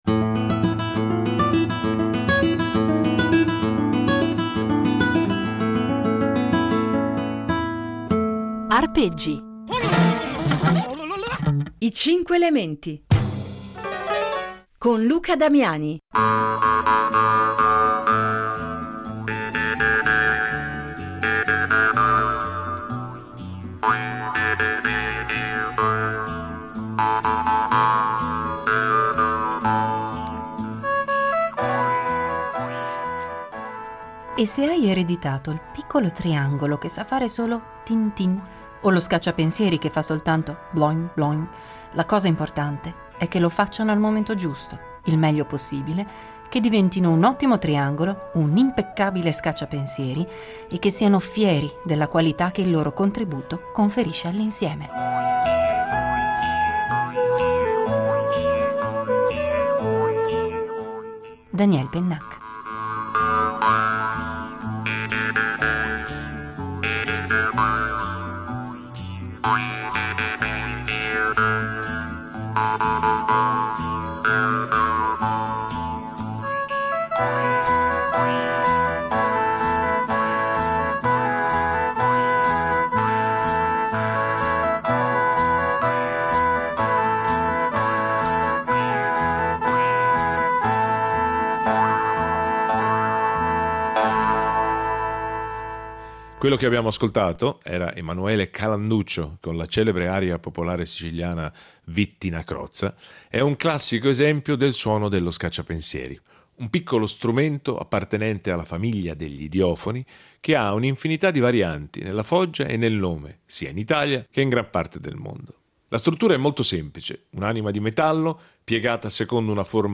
Scacciapensieri (10./10)
Porta quindi con sé un suo peculiare universo sonoro che vale la pena di essere esplorato come tale, ovvero come il riverbero musicale della sua più intima natura. In questa serie di Arpeggi presentiamo quindi i singoli strumenti con brevi esempi che ne illustrano le potenzialità artistiche